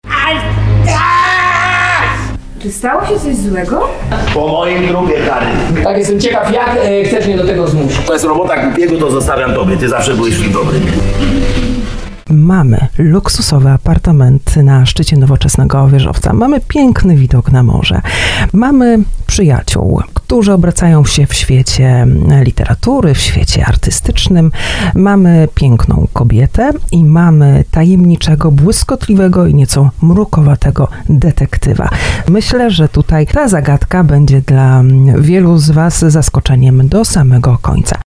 zachęcała na antenie RDN Małopolska